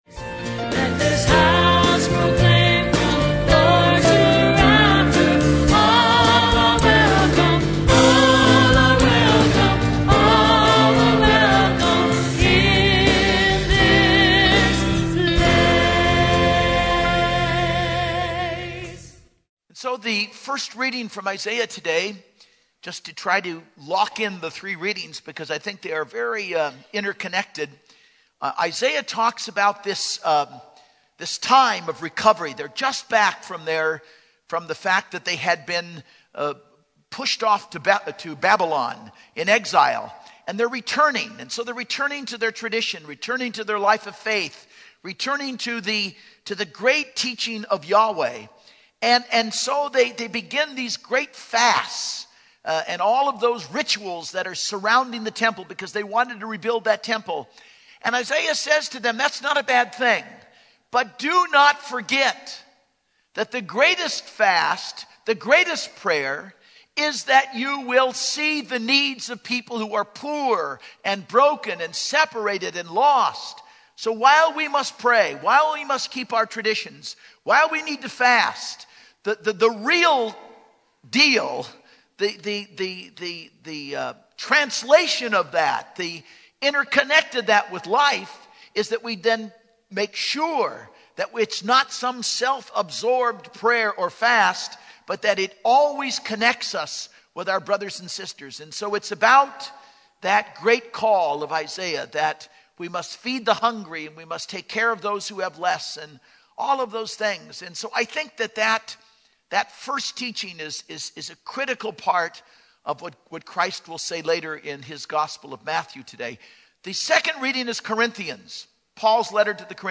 Homily - 2/6/11 - 5th Sunday Ordinary Time - St Monica Catholic Community Media Center